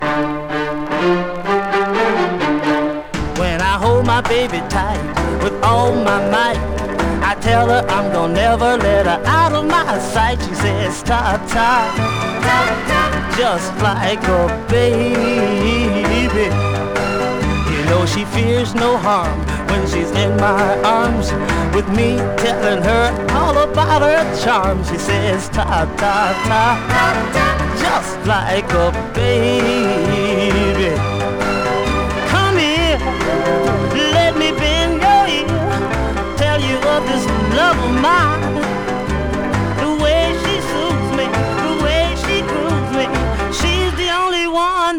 R&B, Pop, Vocal　USA　12inchレコード　33rpm　Stereo